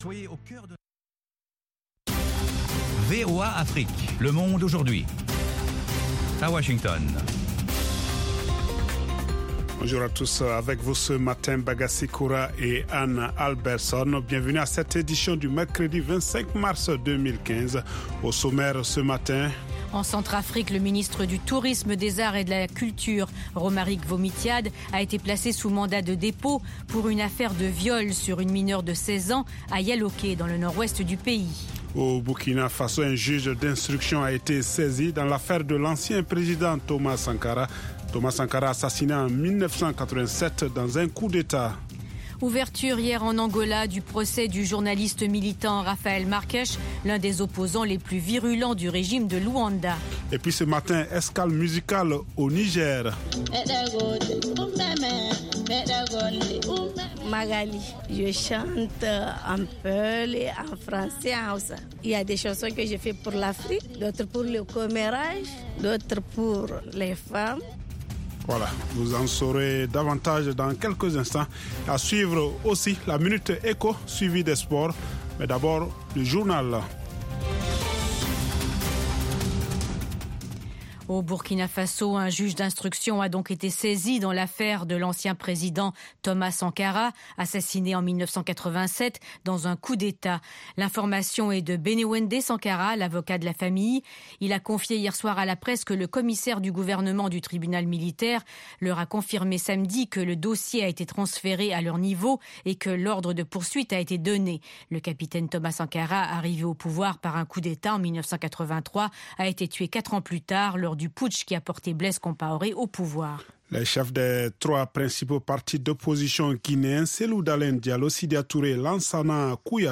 Toute l’actualité sous-régionale sous la forme de reportages et d’interviews. Des dossiers sur l'Afrique etle reste du monde. Le Monde aujourd'hui, édition pour l'Afrique de l’Ouest, c'est aussi la parole aux auditeurs pour commenter à chaud les sujets qui leur tiennent à coeur.